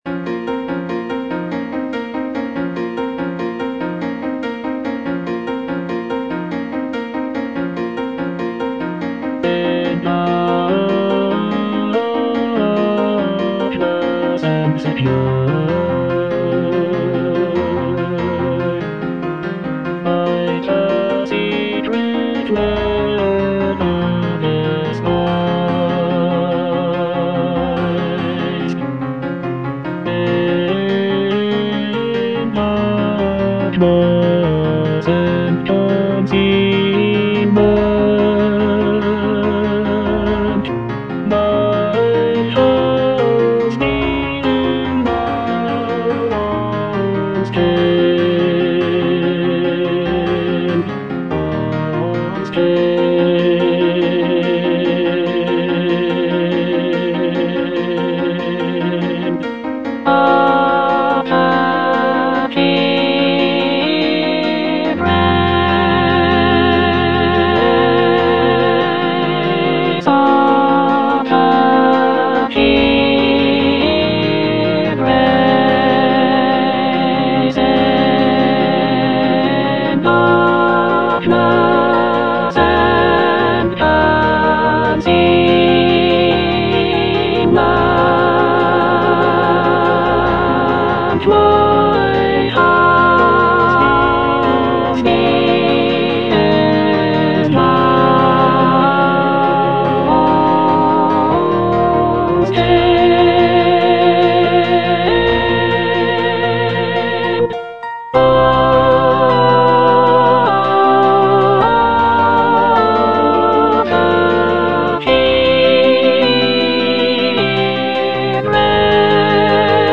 (soprano II) (Emphasised voice and other voices) Ads stop
choral work